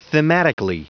Prononciation du mot thematically en anglais (fichier audio)
Prononciation du mot : thematically